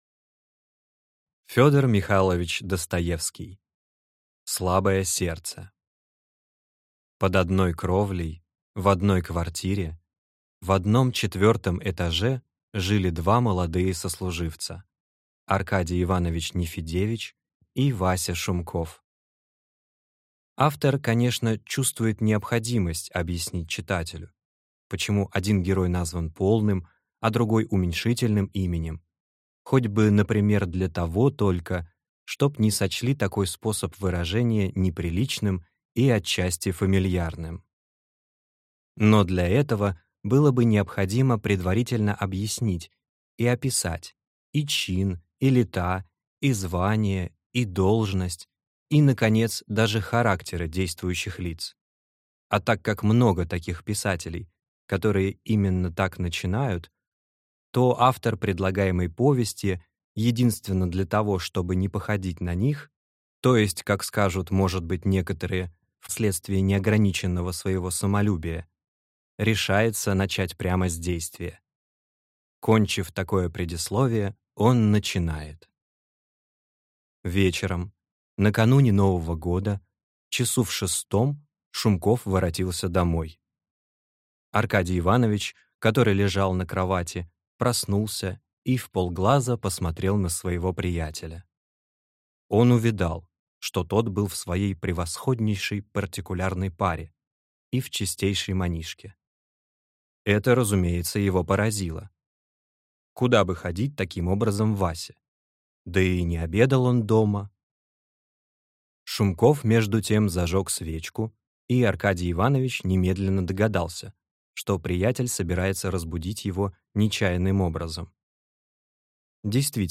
Аудиокнига Слабое сердце | Библиотека аудиокниг